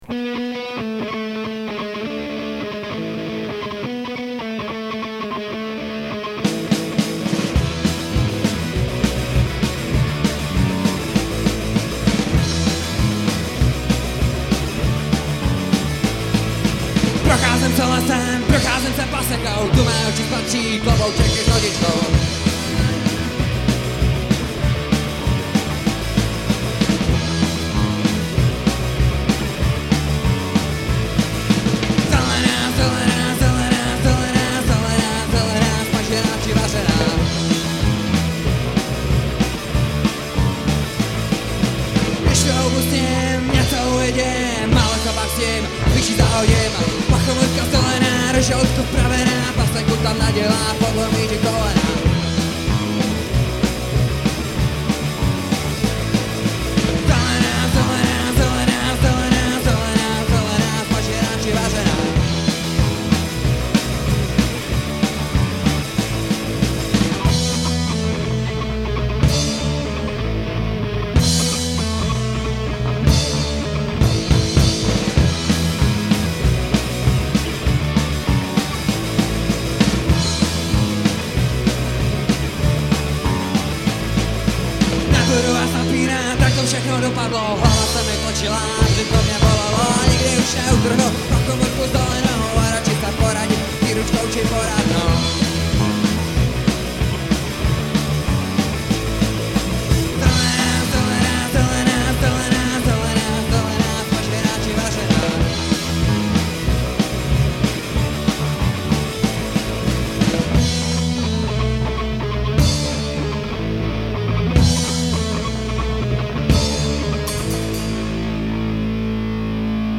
Nahráno živě na koncertu v klubu Prosek dne 1.3.2001